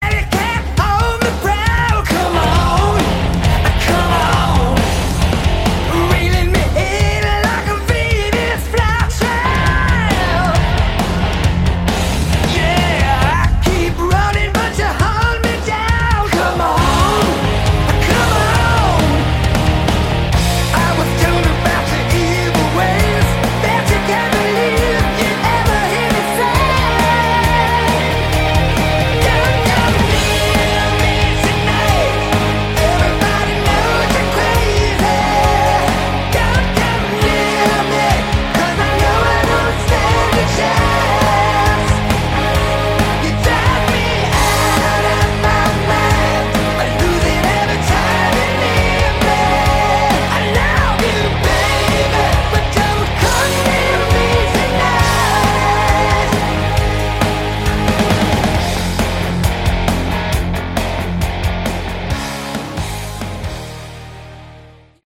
Category: AOR
lead and backing vocals, acoustic guitar, bass
backing vocals
keyboards
drums
guitars